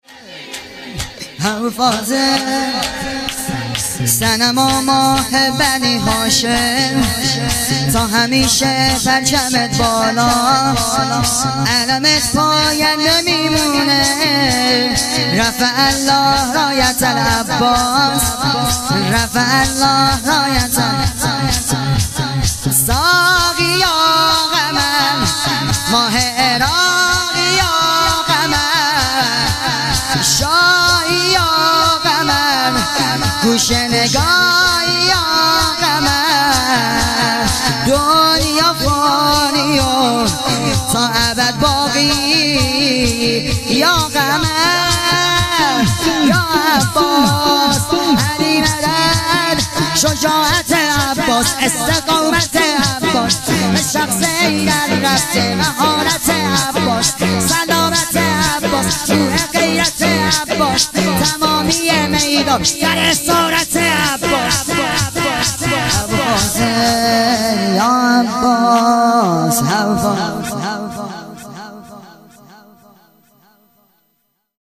شور زیبا حضرت ابالفضل العباس